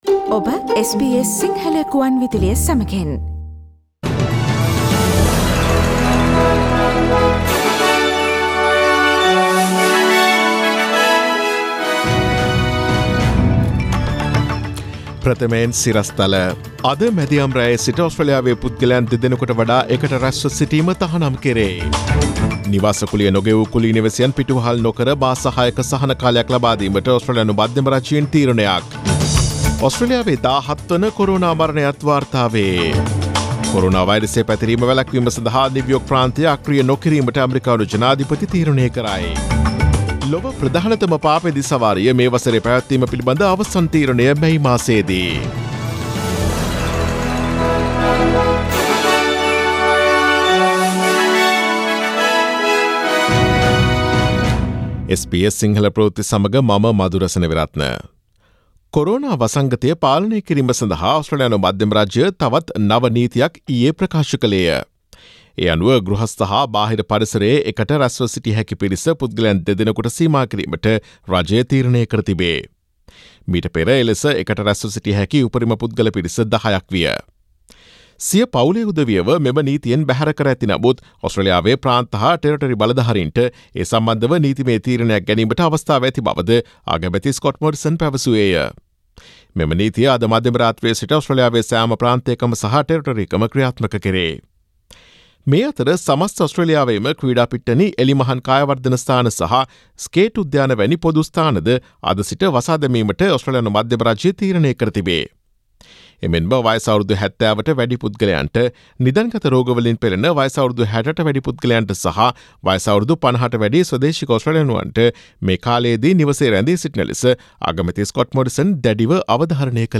Daily News bulletin of SBS Sinhala Service: Monday 30 March 2020